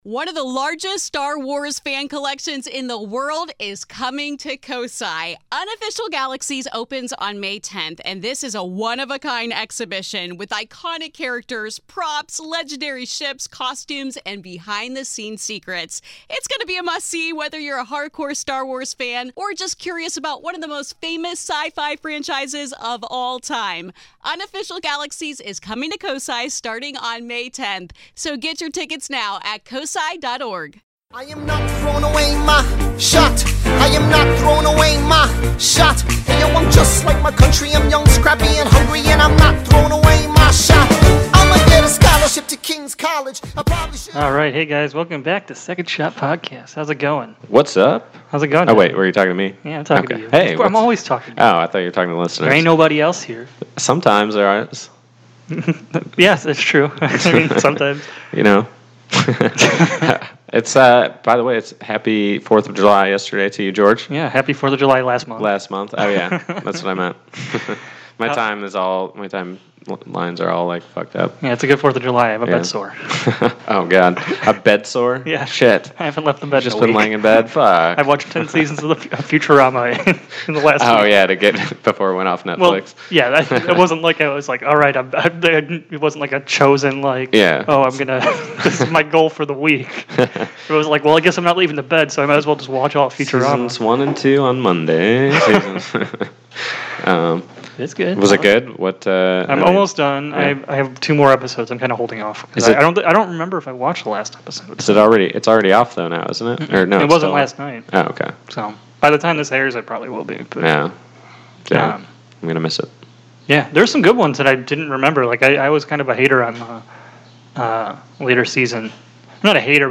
This week is not for those who don't like mouth sounds.
The true professionals we are, we eat on the mic and make the best mouth sounds.